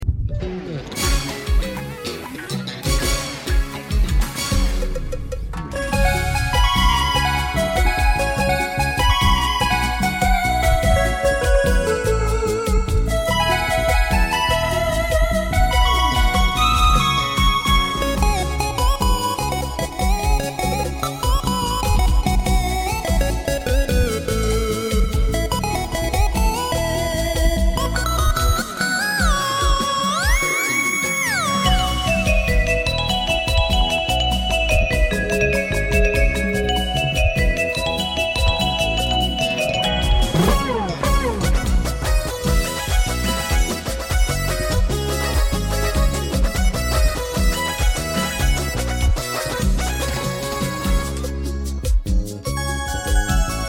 SAMPLES KUMBIA FUSION PERDONAME pt1